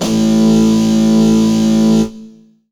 gtdTTE67001guitar-A.wav